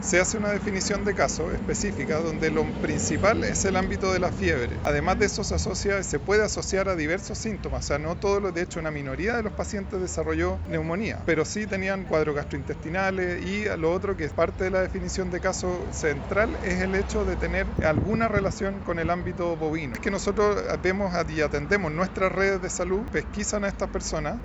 «Nuestros productos lácteos y cárneos son muy seguros: así que el llamado es a consumir leche pasteurizada.